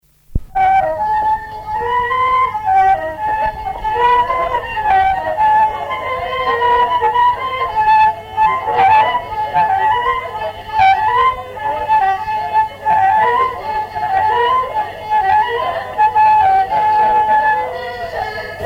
Danse bretonne par La guenille à Pierrot
danse : an dro
Répertoire d'un bal folk par de jeunes musiciens locaux
Pièce musicale inédite